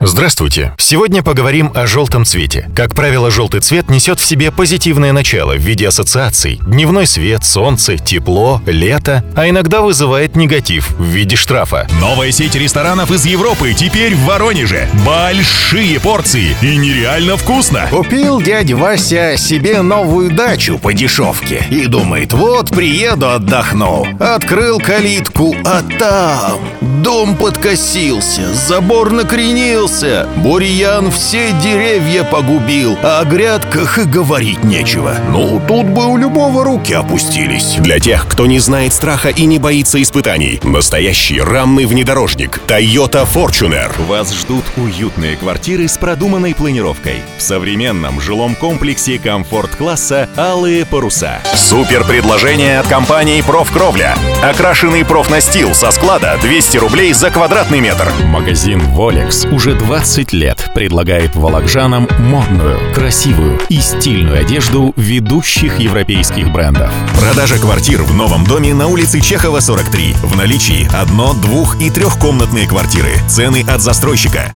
Тракт: Микрофон Neumann TLM103, Ламповый предусилитель/компрессор - Presonus ADL700.
Акустически обработанная панелями voice-booth ("микрофонка").
Демо-запись №2 Скачать